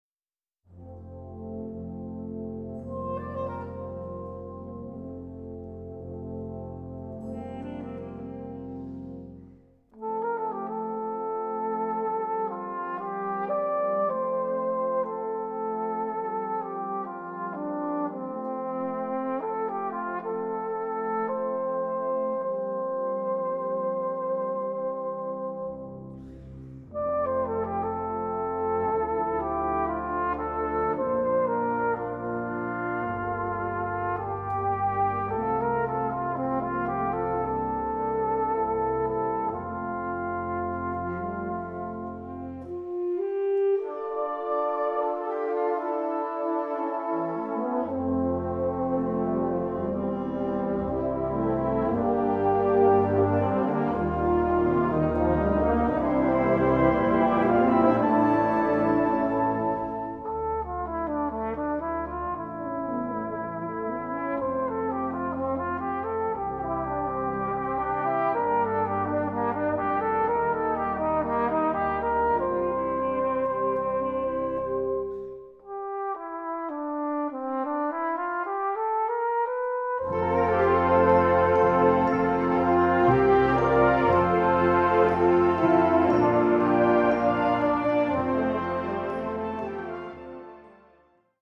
Répertoire pour Harmonie/fanfare - Bugle et Fanfare